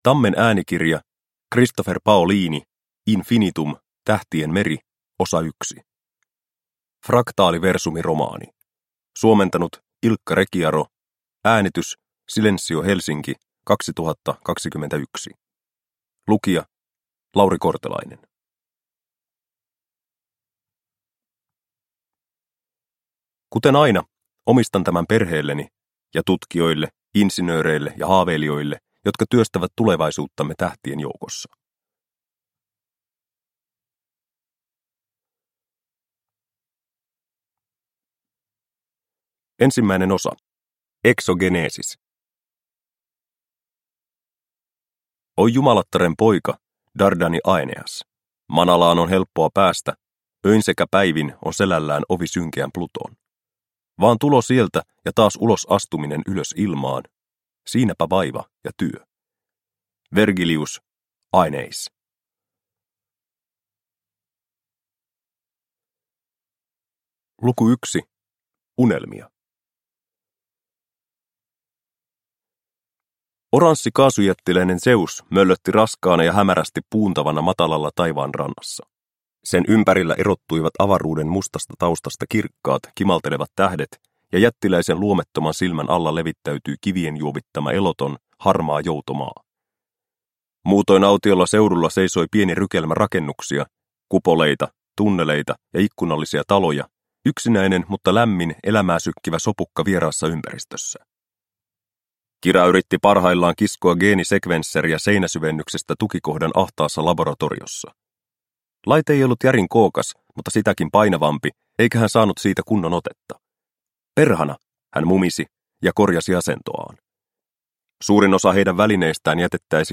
Infinitum. Tähtien meri. Osa 1 – Ljudbok – Laddas ner